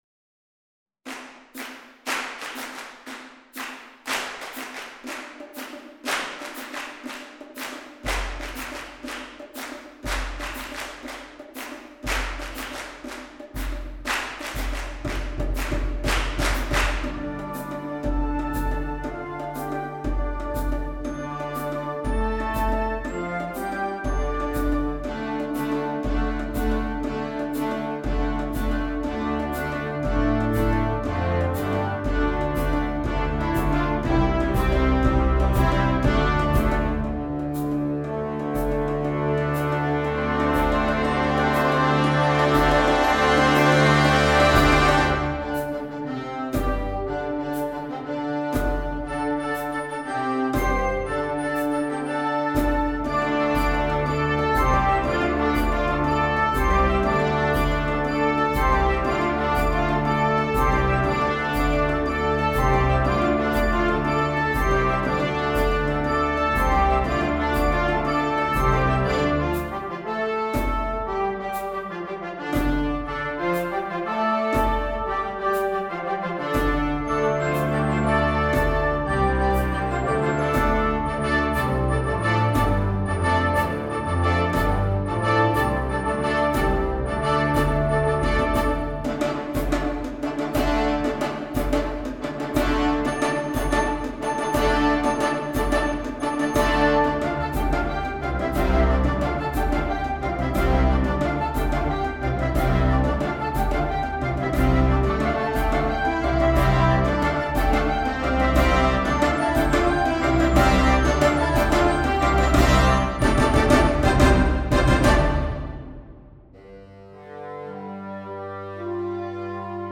Gattung: Suite für Blasorchester
Besetzung: Blasorchester